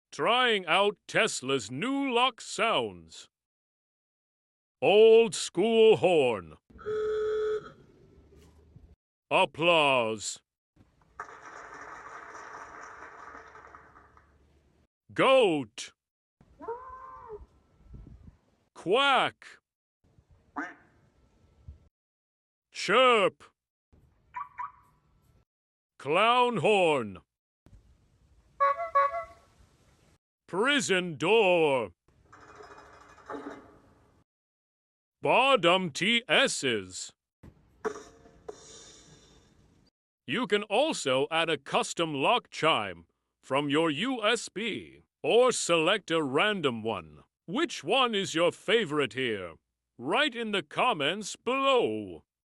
Tesla's new lock sounds following sound effects free download